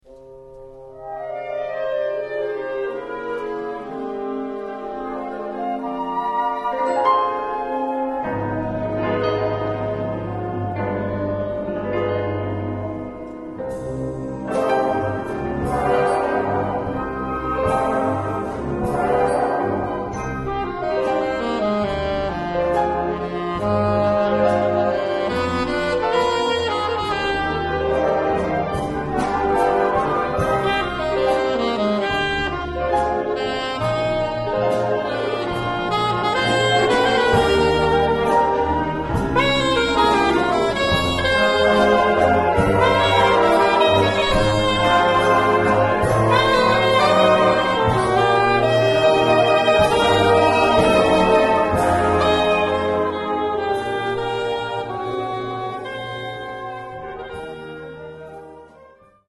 Besetzung: Altsaxophon Solo & Blasorchester